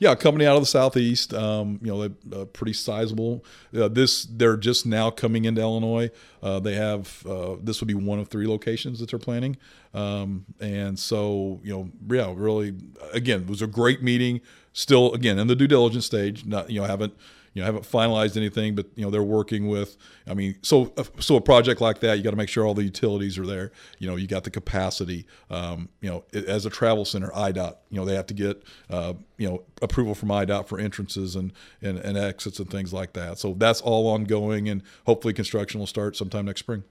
Speaking during our weekly podcast “Talking about Vandalia,” Mayor Doug Knebel says the Business District for a very specific part of town is now ready to move forward.